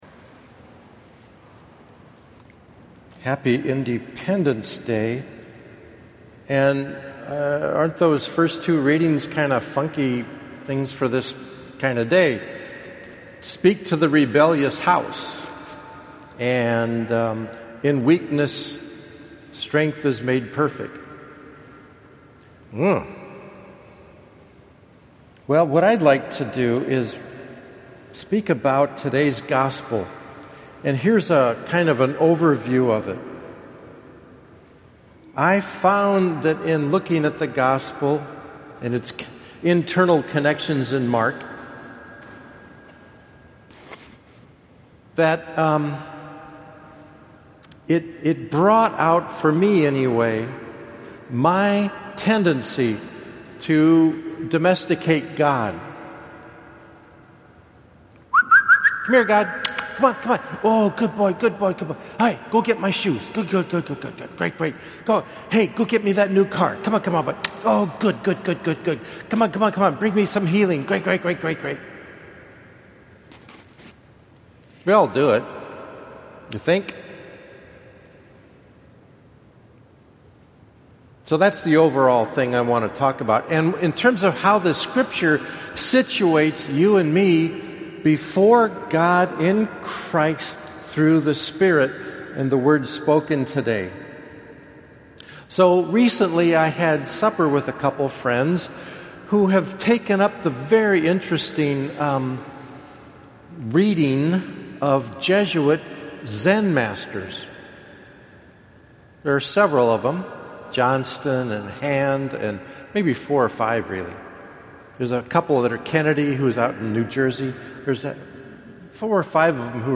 Fourteenth Sunday of Ordinary Time (4:30 pm Saturday)